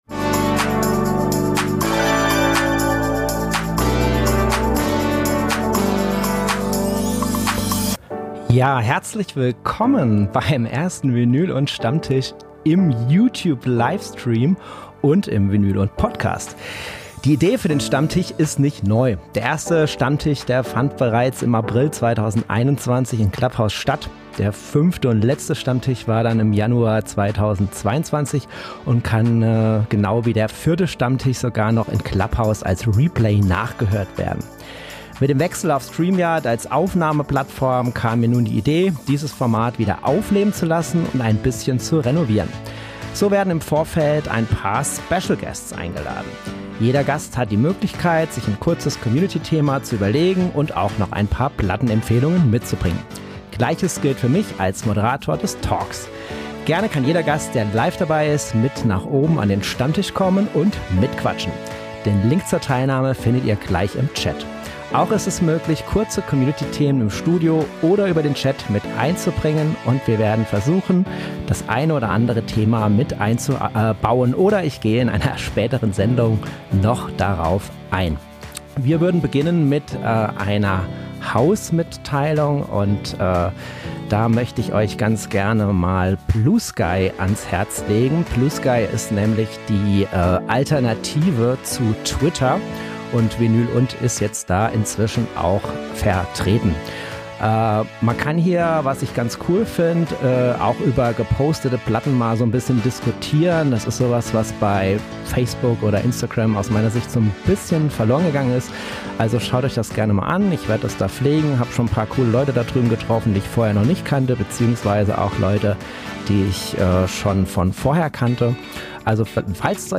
Die Antworten waren genau so kontrovers wie die Diskussionen am Vinyl & ... Stammtisch in der Livesendung.